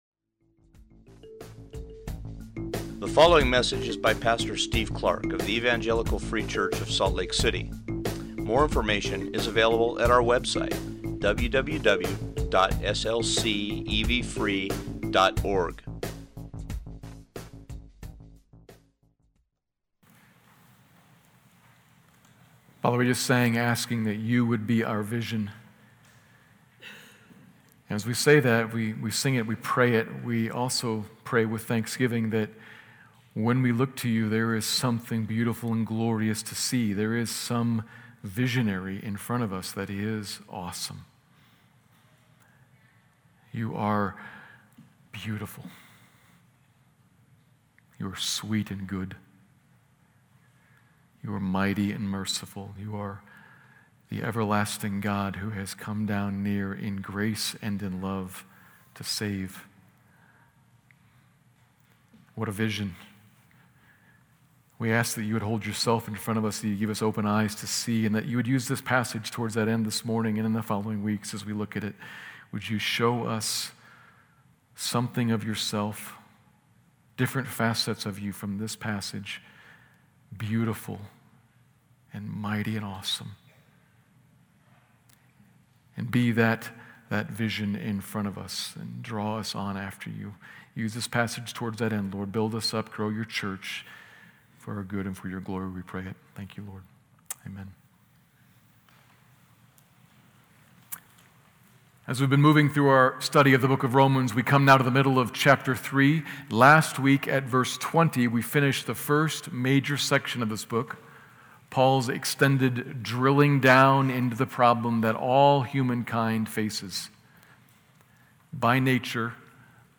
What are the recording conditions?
Sermons of the Evangelical Free Church of Salt Lake City